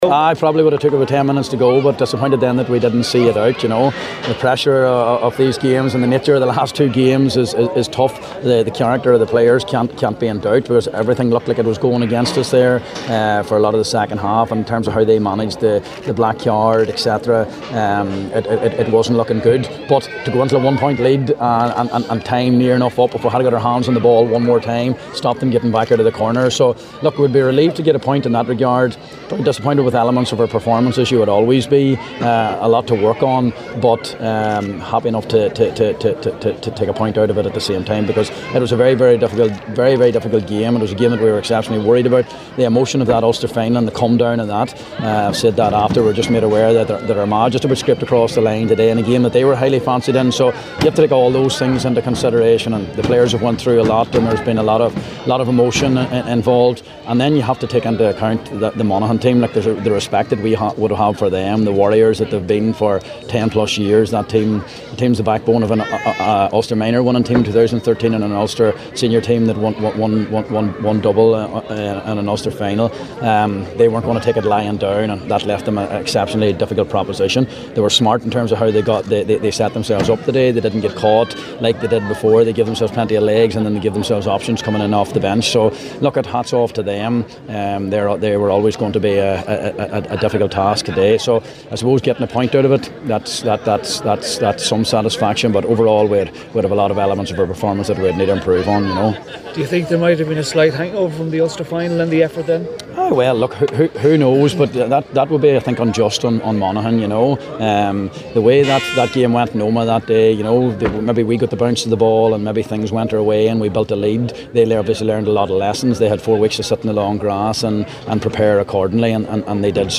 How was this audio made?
spoke with the media after the game…